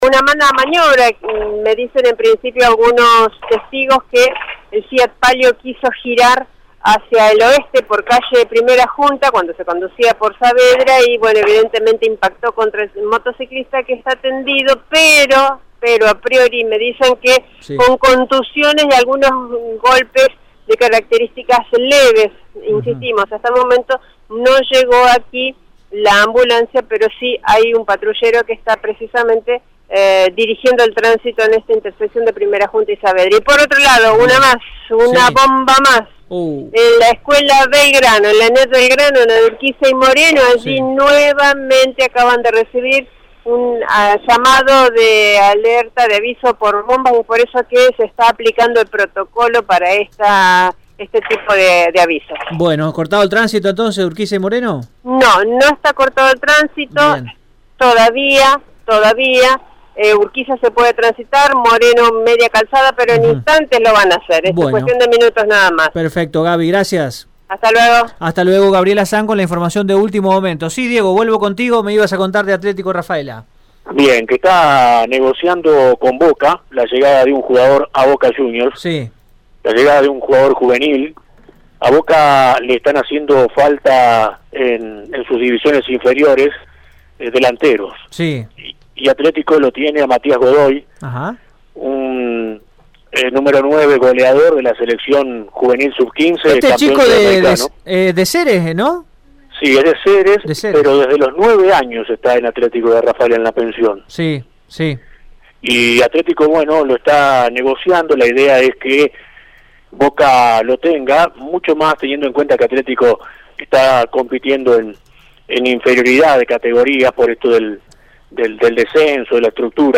Así lo contó el móvil de Radio EME.